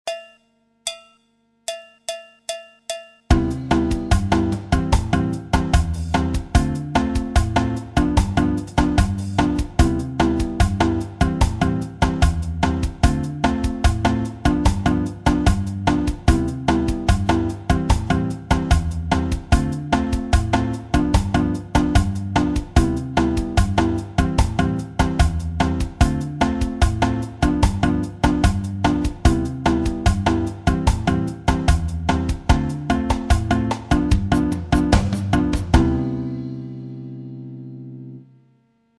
La bossa nova en anatole
Nouvelle figure tirée de la figure bossa 1avec variation harmonique en anatole sur les accords suivants. C7M, Am7, Dm7, G7.